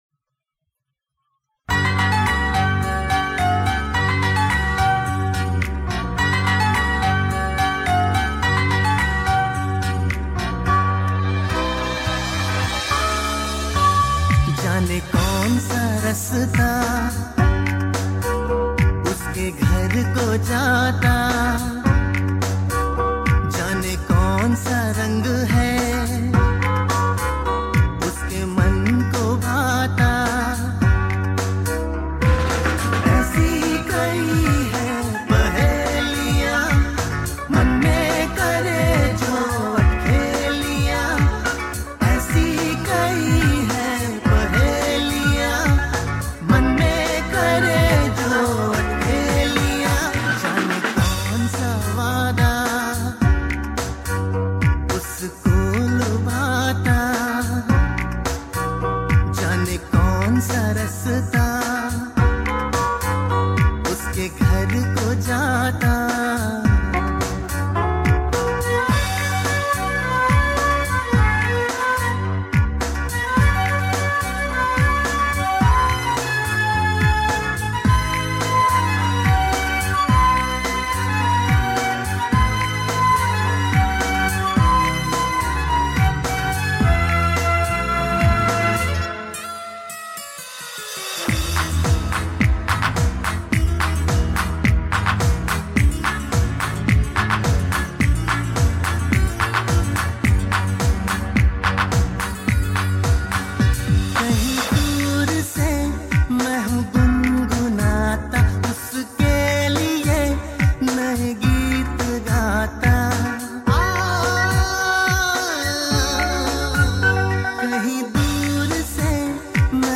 2. INDIPOP MP3 Songs